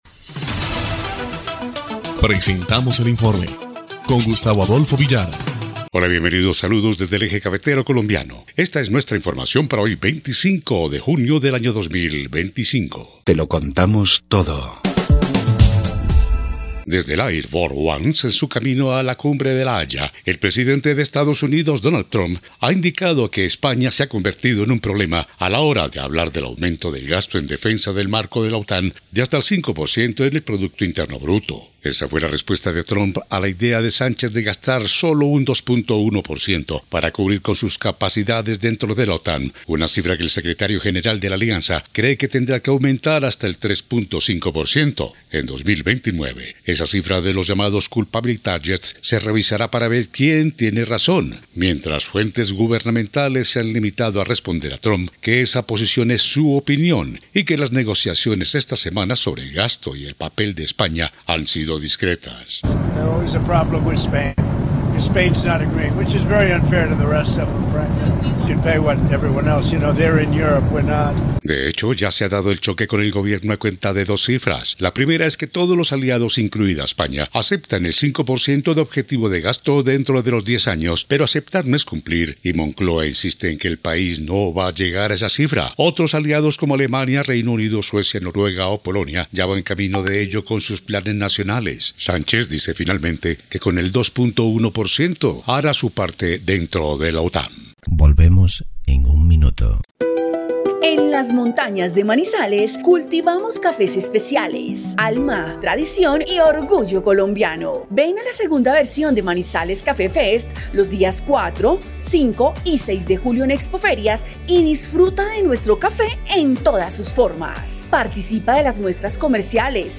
EL INFORME 1° Clip de Noticias del 25 de junio de 2025